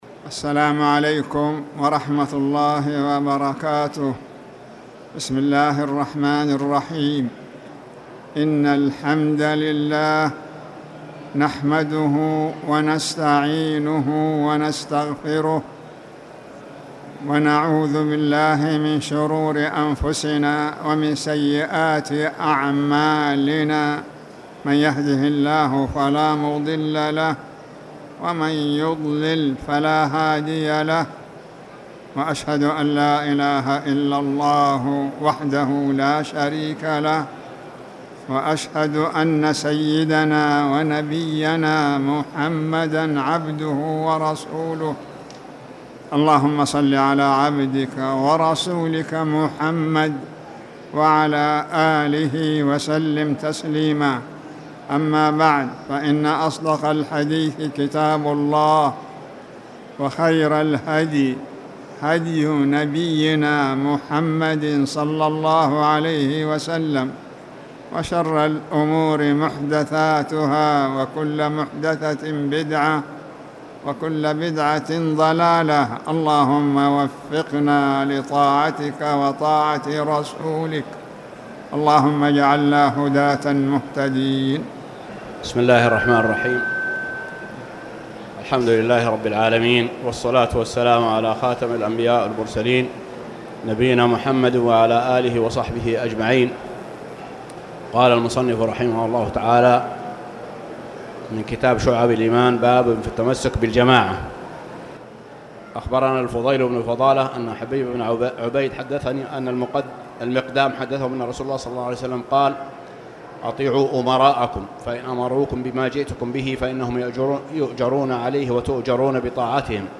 تاريخ النشر ١٢ ربيع الأول ١٤٣٨ هـ المكان: المسجد الحرام الشيخ